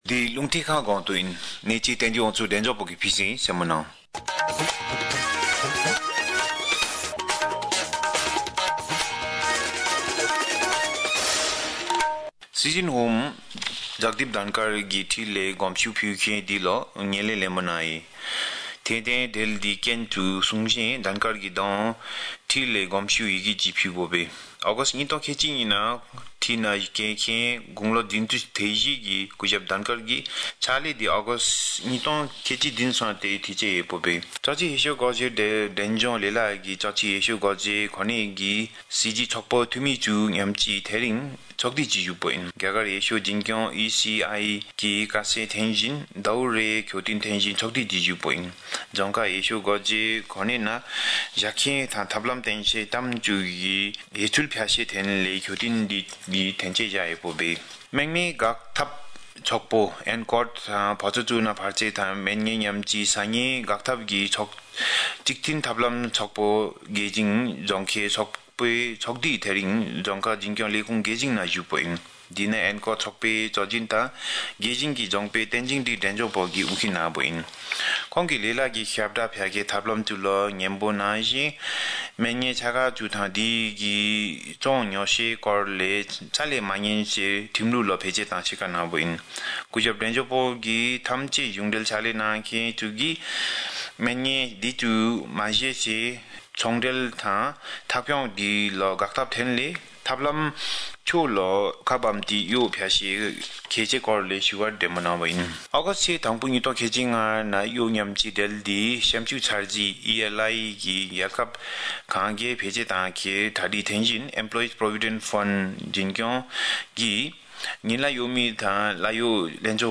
Bhutia-News-1850.mp3